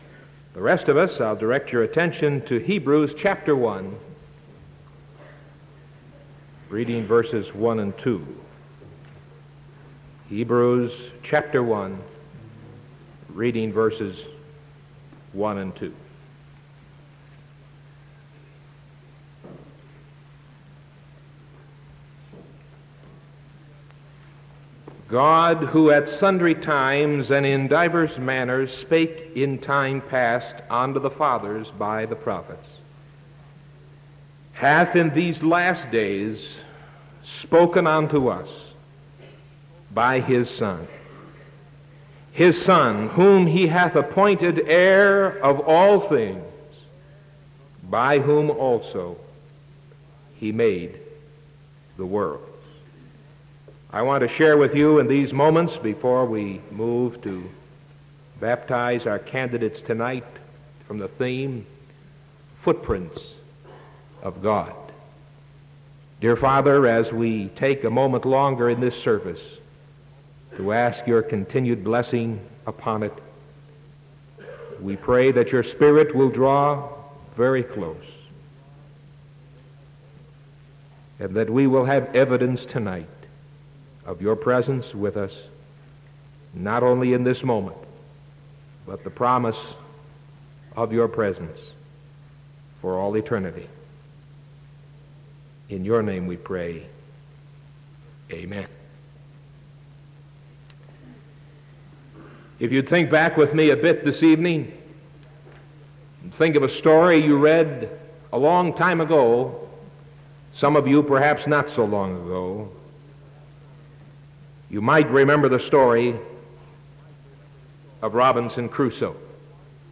Sermon April 20th 1975 PM